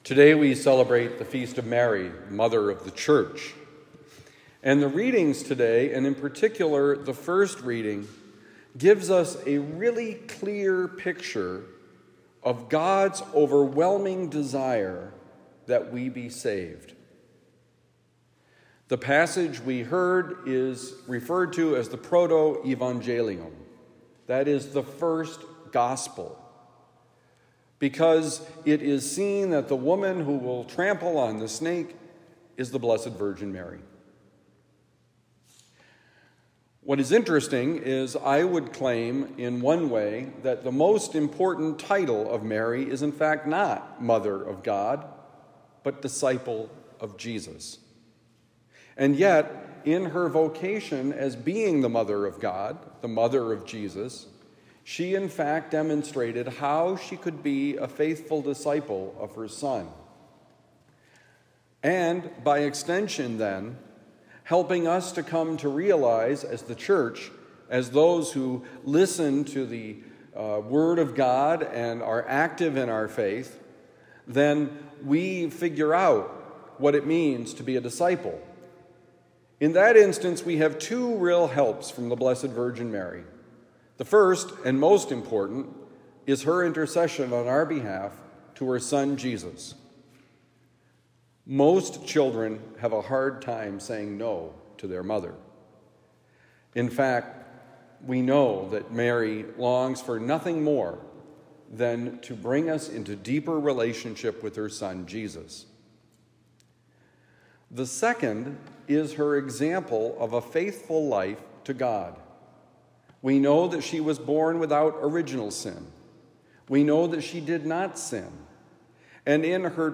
Mary, Mother of the Church: Homily for Monday, May 20, 2024